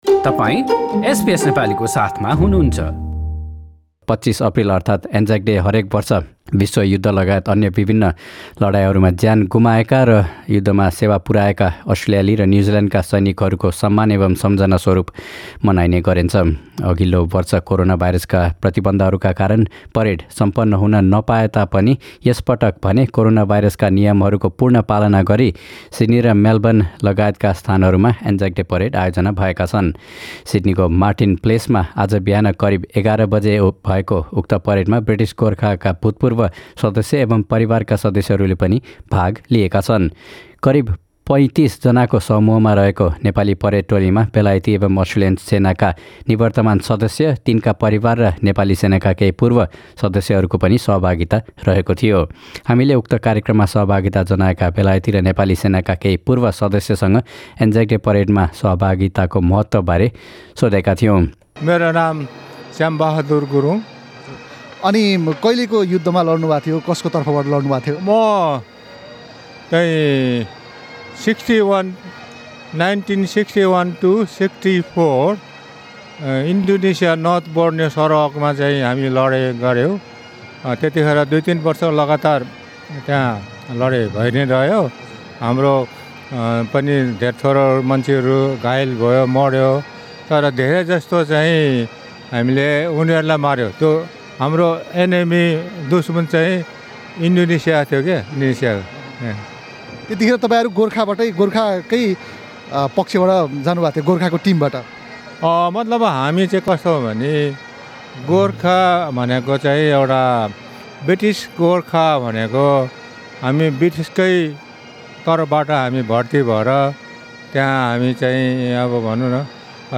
यसैक्रममा सिड्नीको मार्टिन प्लेसमा बाट सुरु भएको परेडमा सहभागी भएका पूर्व ब्रिटिश र नेपाली सेनाका सदस्यहरुले एसबीएस नेपालीसंग गरेको कुराकानी।